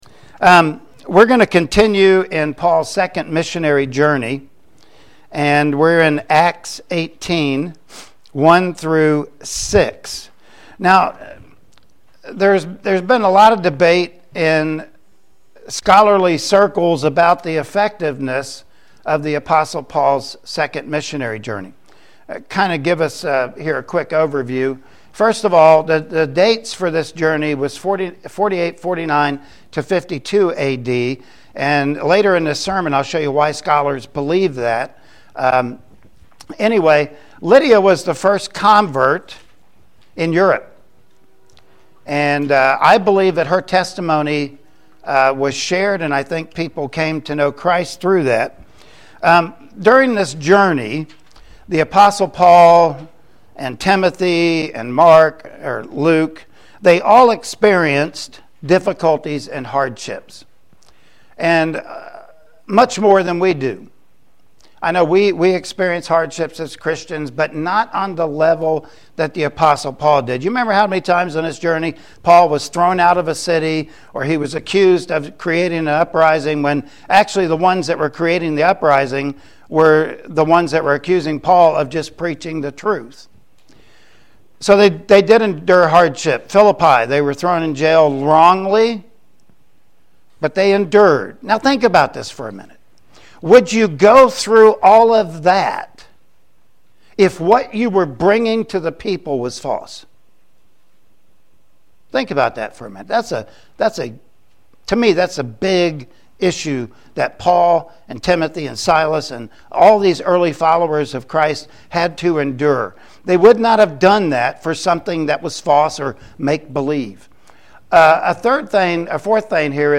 Acts 18:1-6 Service Type: Sunday Morning Worship Service Topics: God's Guidance